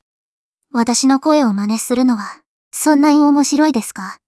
ここでは、1つ目の音声およびそのスクリプトを基に、2個目の音声を生成しています。生成された音声品質がかなり高いことがわかります。